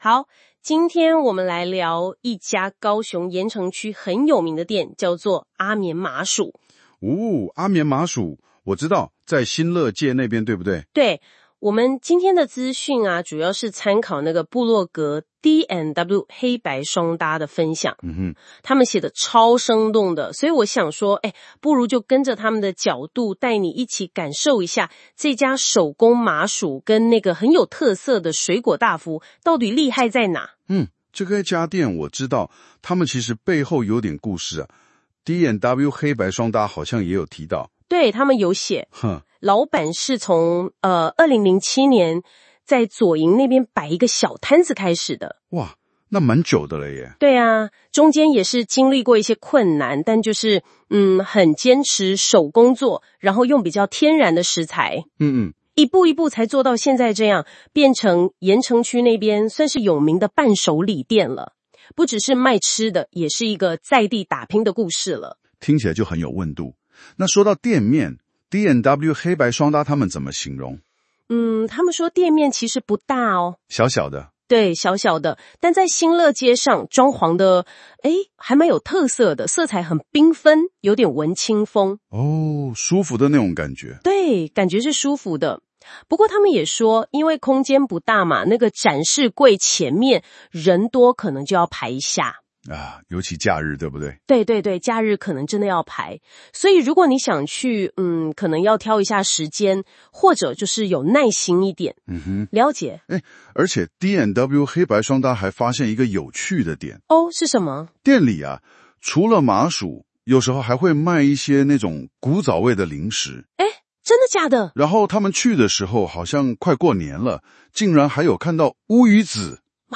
新功能!現在用【說】的方式介紹哦!
我們請兩位主持人專業講解，深度介紹D&W黑白雙搭推薦內容